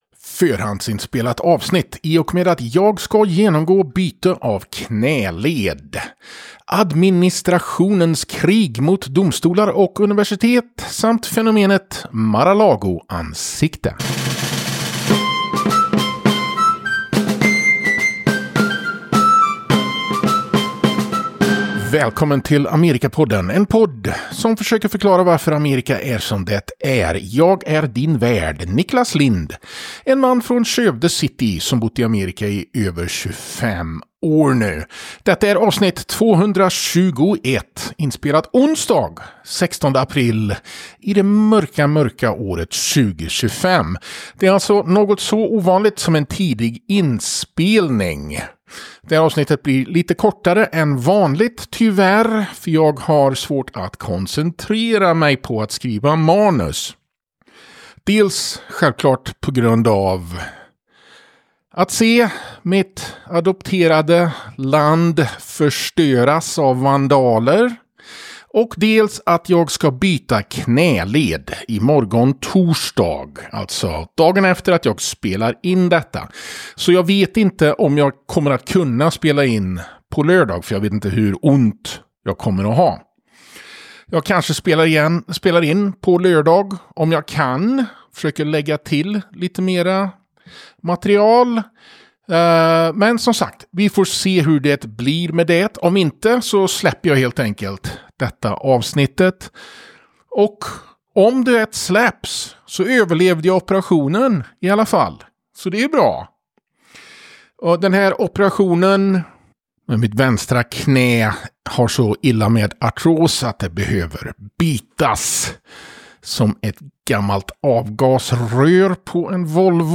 Förhandsinspelat avsnitt i och med att jag ska genomgå byte av knäled. Administrationens krig mot domstolar och universitet, samt fenomenet Mar-a-Lago ansikte.